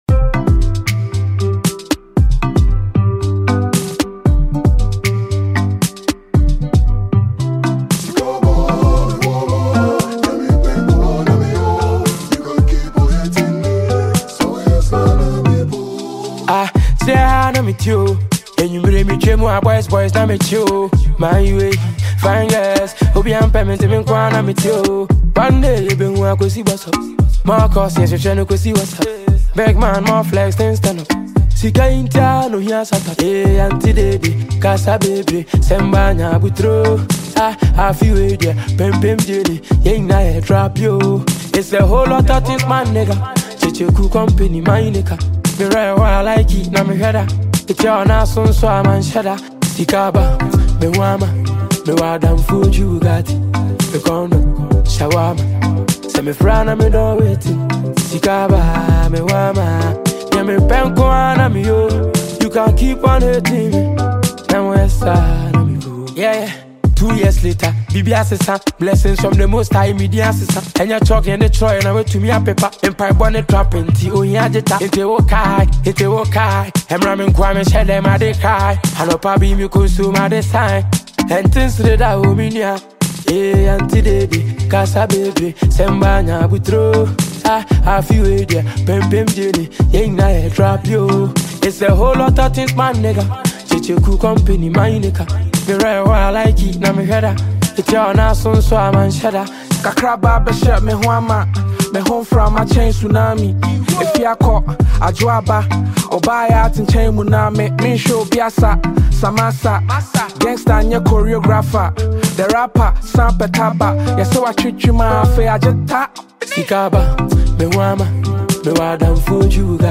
Ghanaian singer and songwriter